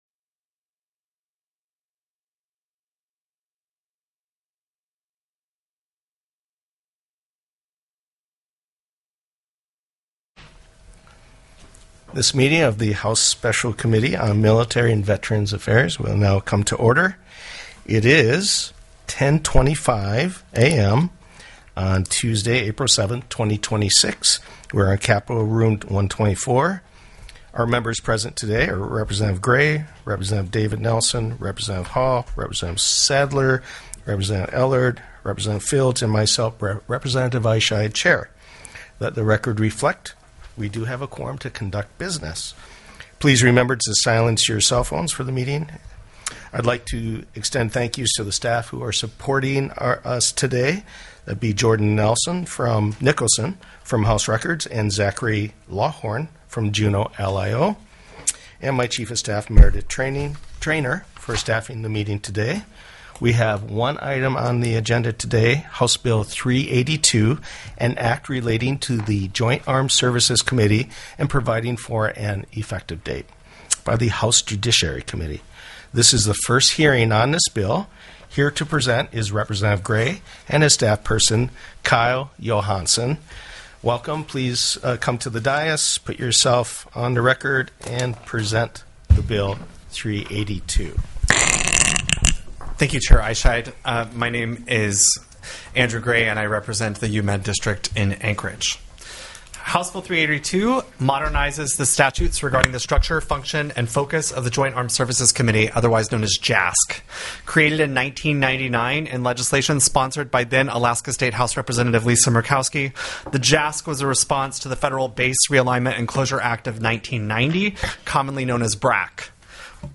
The audio recordings are captured by our records offices as the official record of the meeting and will have more accurate timestamps.
Heard & Held -- Invited & Public Testimony